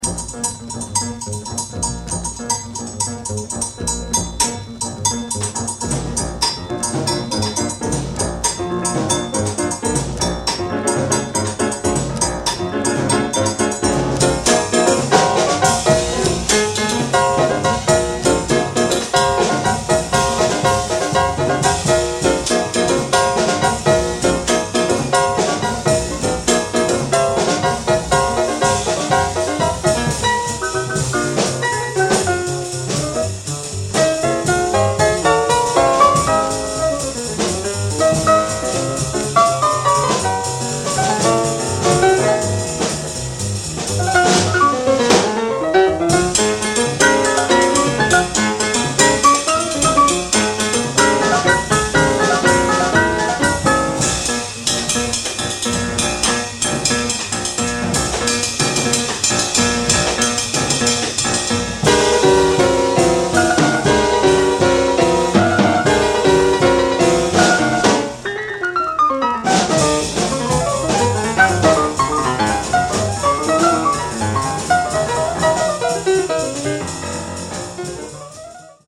1st press, stereo.